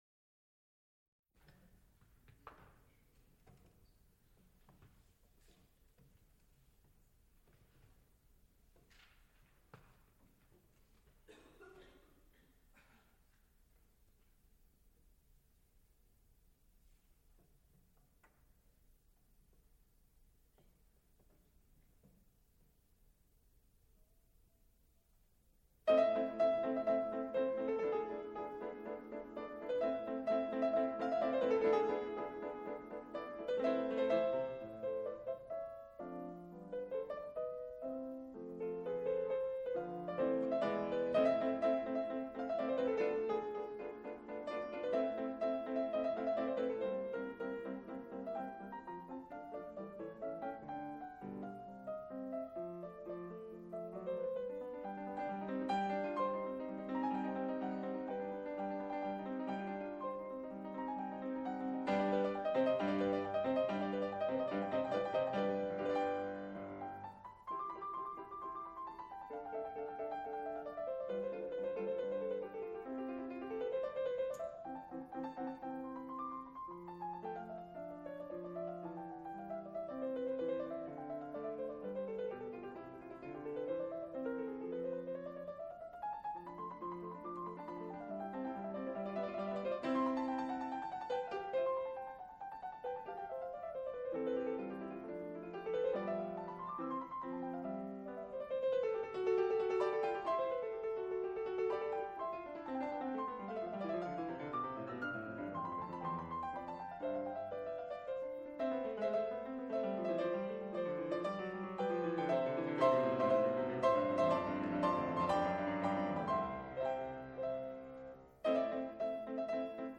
Recorded live February 17, 1977, Frick Fine Arts Auditorium, University of Pittsburgh., Mozart, Sontat in A minor; Webern, Variations Op. 27
musical performances
Sonatas (Piano) Variations (Piano)